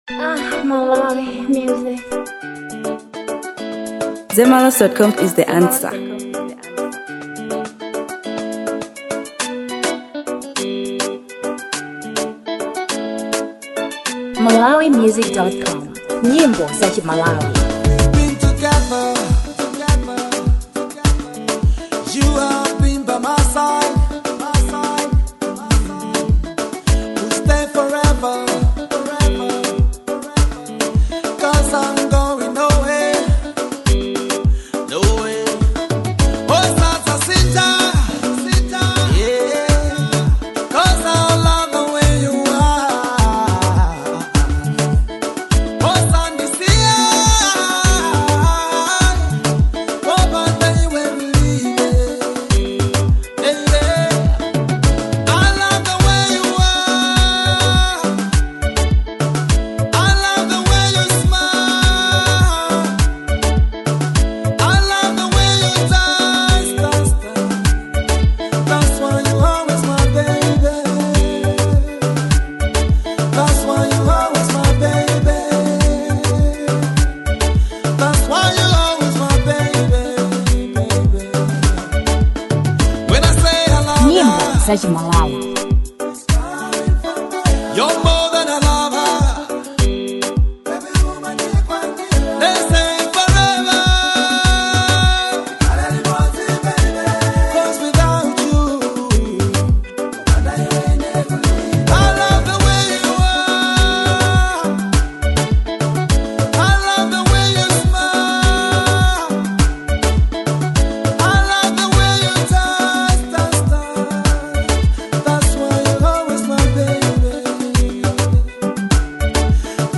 Fusion • 2025-10-27